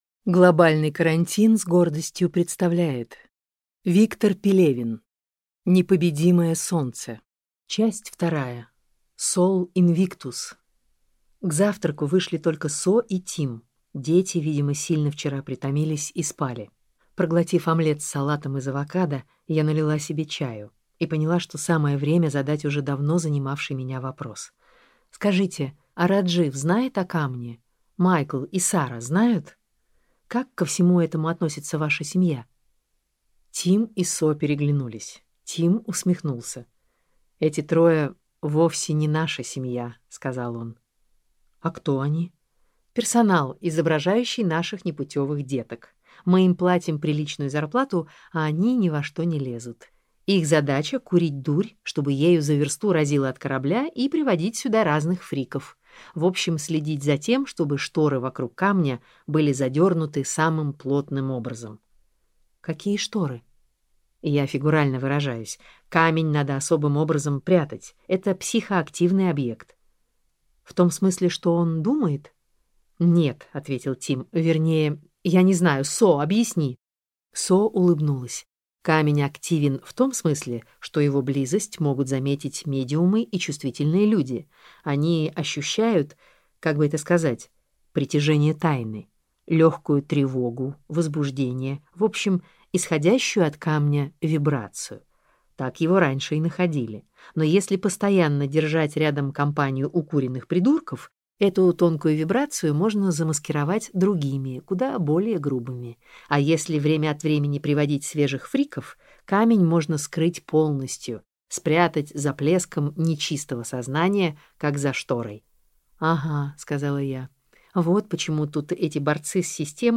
Аудиокнига Непобедимое солнце. Книга 2 - купить, скачать и слушать онлайн | КнигоПоиск